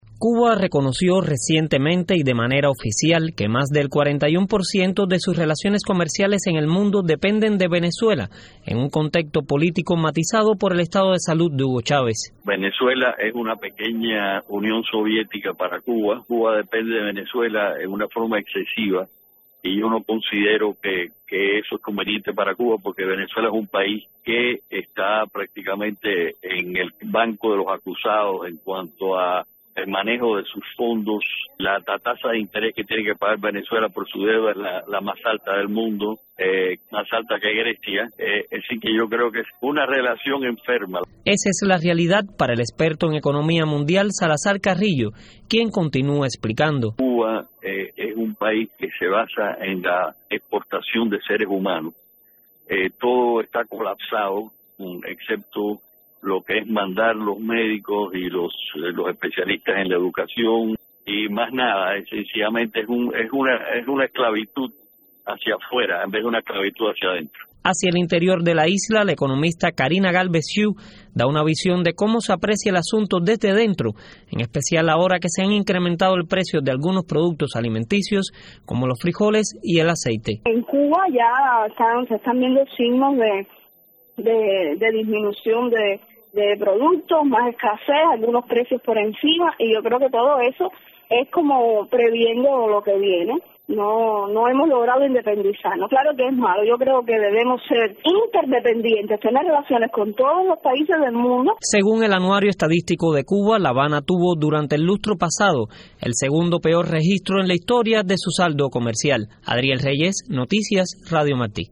El alto grado de dependencia económica de Cuba respecto a Venezuela y el aumento de los precios de algunos productos alimenticios en la isla constituye un tema de preocupación para economistas de dentro y fuera del país. Con más detalles el reportaje